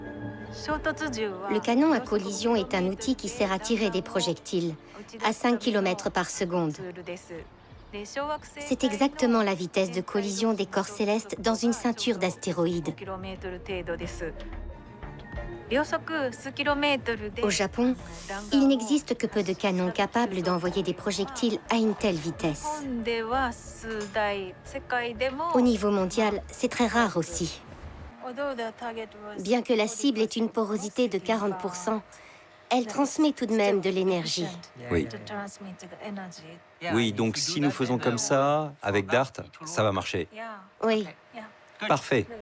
voix femme documentaire France 5
J'ai une voix jeune, légère, cristalline et pétillante...